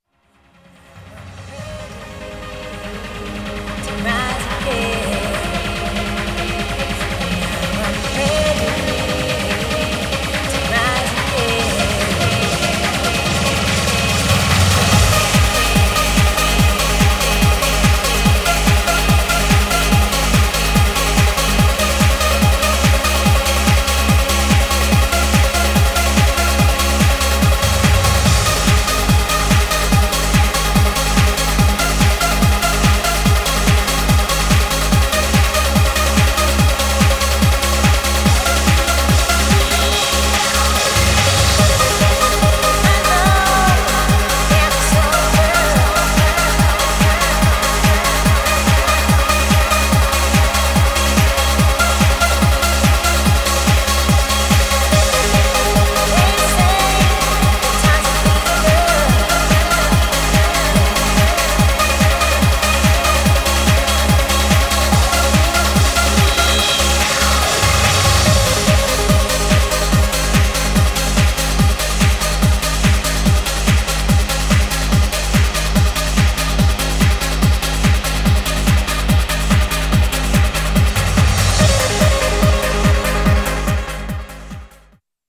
Styl: Progressive, House, Breaks/Breakbeat, Trance